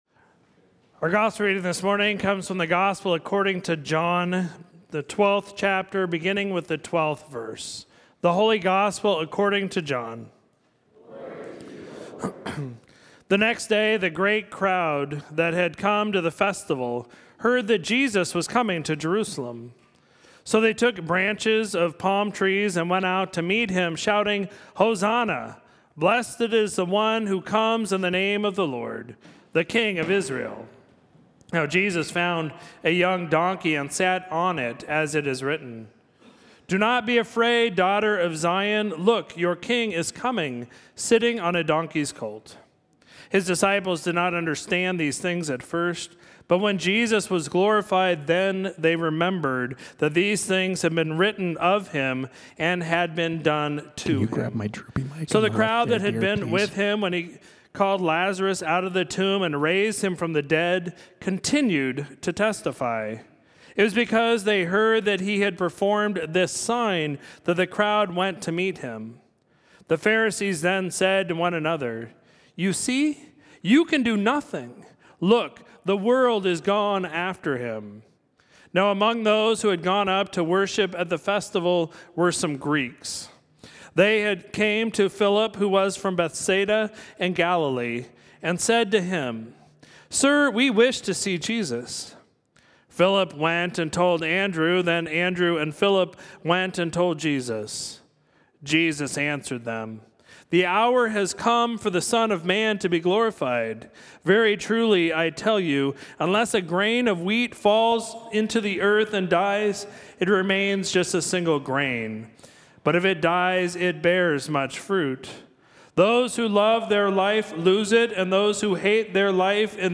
Sermon for Sunday, April 10, 2022